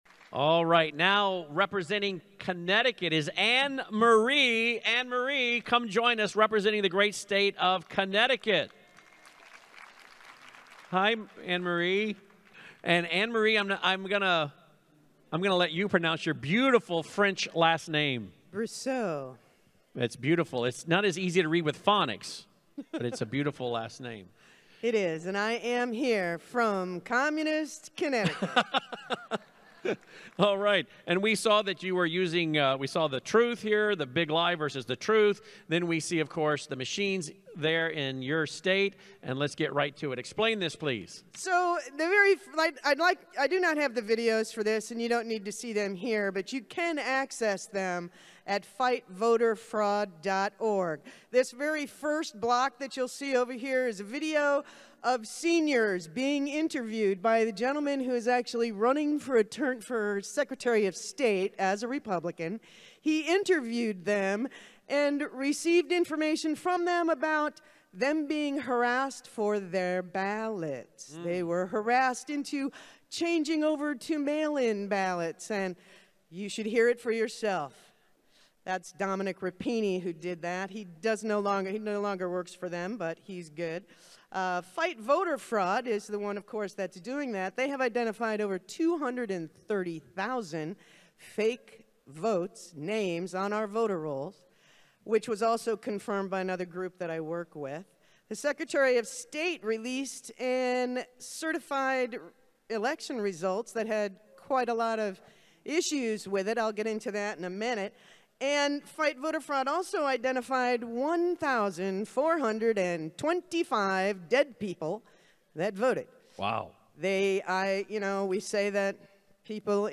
2022 CT Moment of Truth Summit State of the States Presentation audio – Cause of America